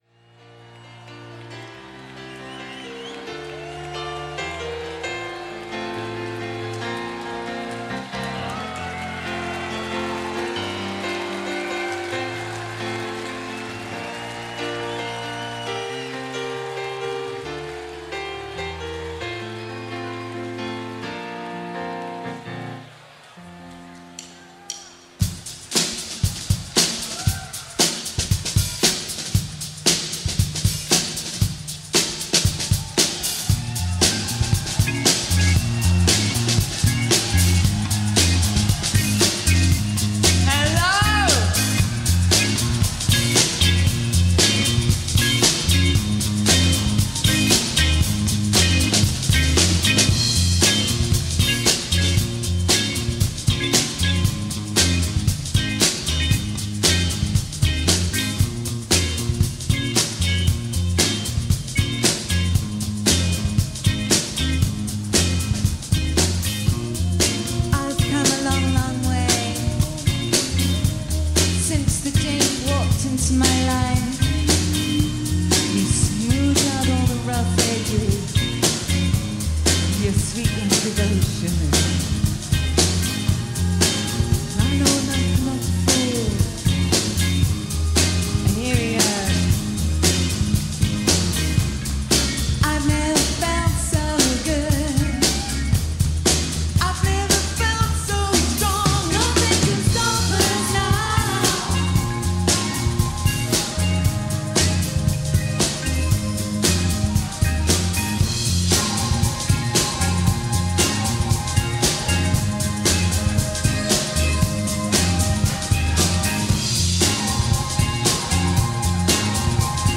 how to stir up a pot of 90s dance club with 60s pop